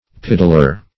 piddler - definition of piddler - synonyms, pronunciation, spelling from Free Dictionary
piddler - definition of piddler - synonyms, pronunciation, spelling from Free Dictionary Search Result for " piddler" : The Collaborative International Dictionary of English v.0.48: Piddler \Pid"dler\, n. One who piddles.
piddler.mp3